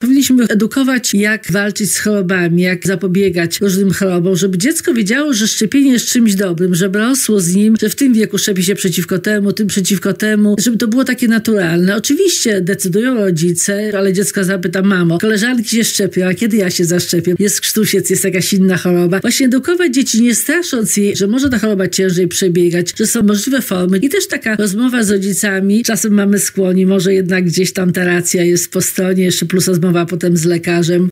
w porannej rozmowie Radia Lublin